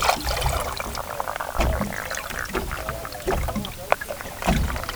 Blue crab spectrogram Spectrogram of Blue crab, Callinectes sapidus sounds recorded in Popponesset Bay on Cape Cod, MA on 24 June 2020. The crab produced sounds only after being released into the water. Sounds may have been bubble release.